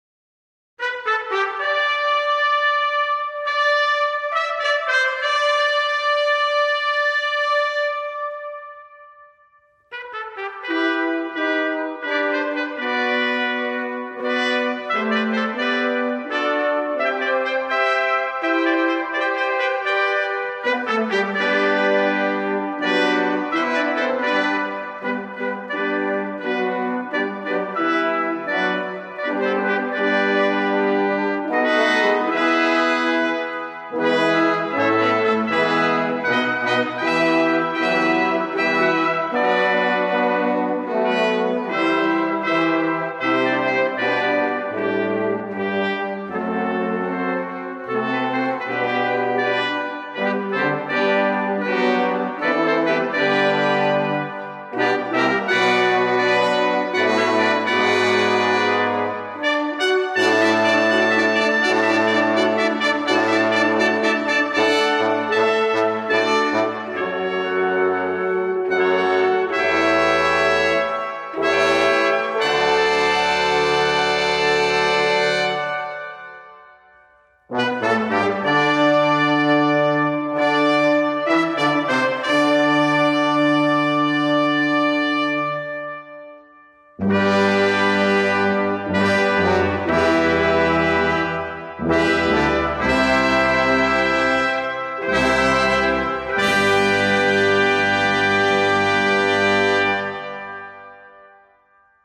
Fanfare1960.mp3